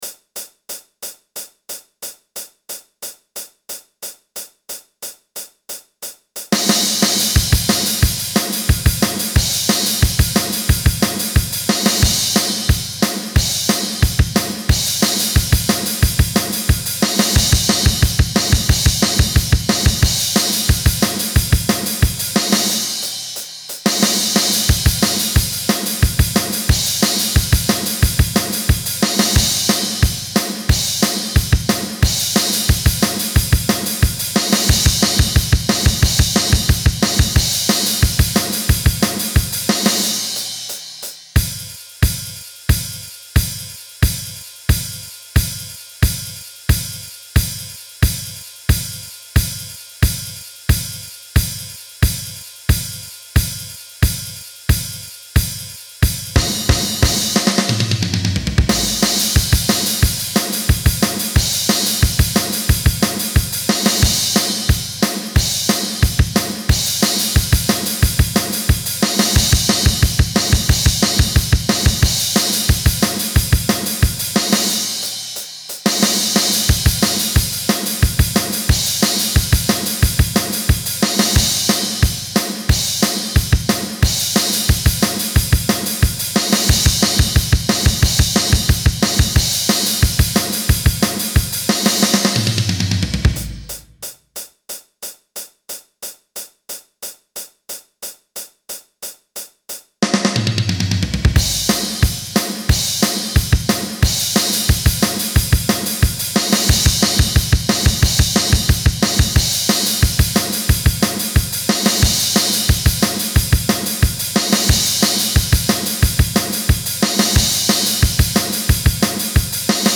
Drums only - create what you want
*TIP:  The song begins with a 4 count on the hi-hat.